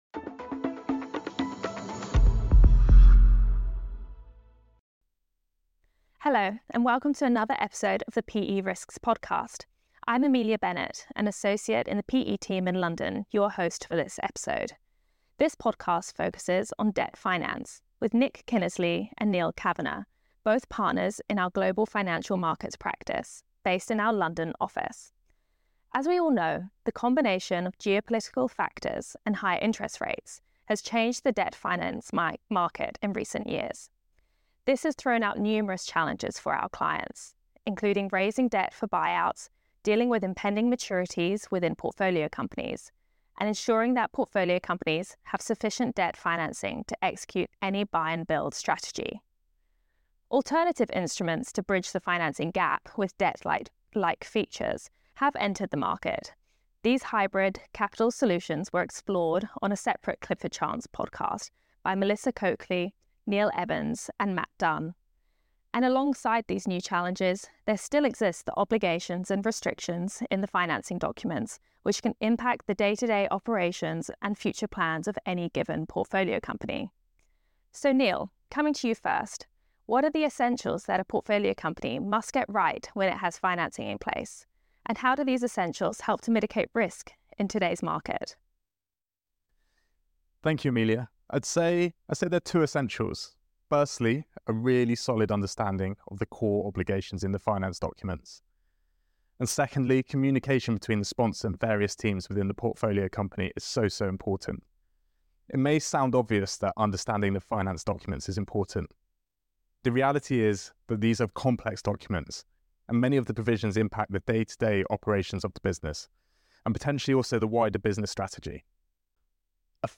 hosts a discussion